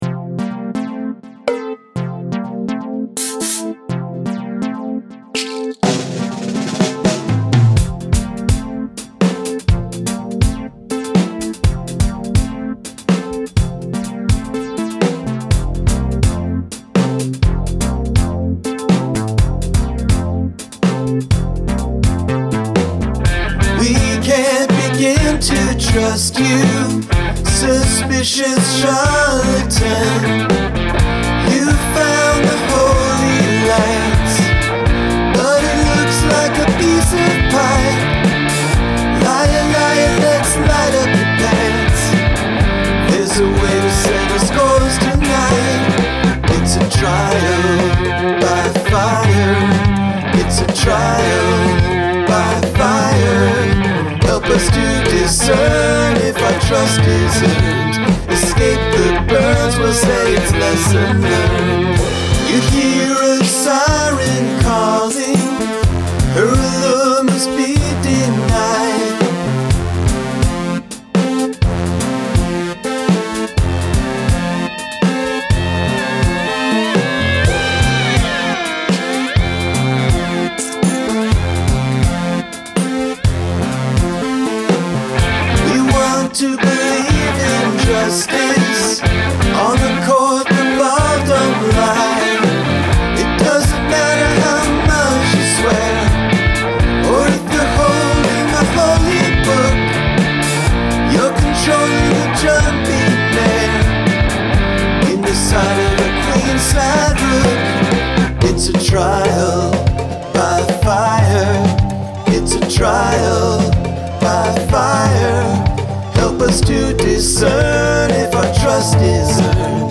Incorporate sirens